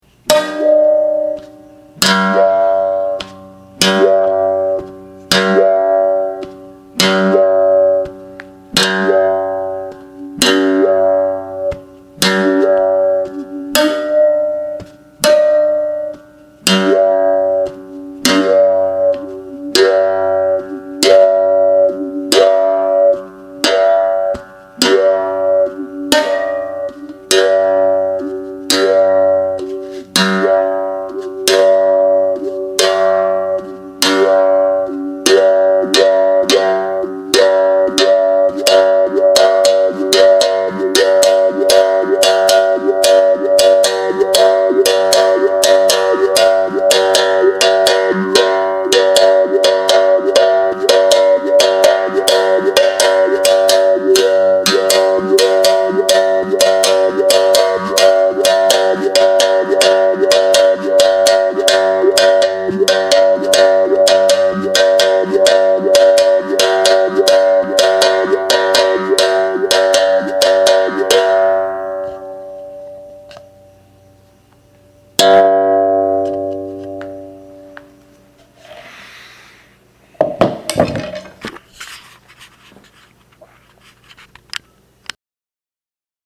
Ouça o som de minha cabaça: